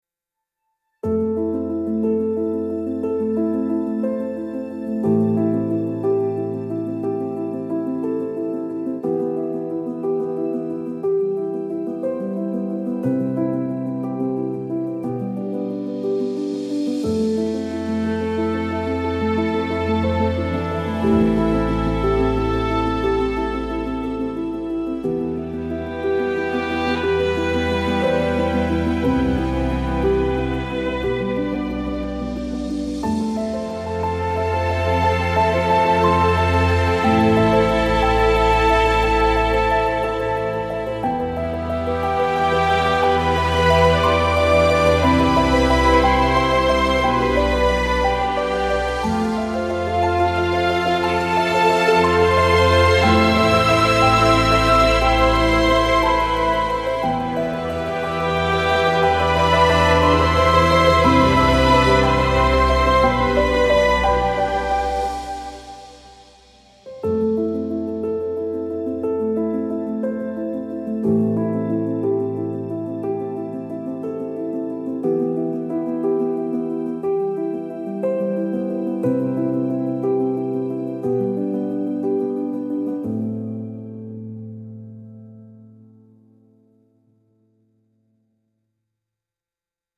gentle orchestral piece inspired by classic Hollywood romance films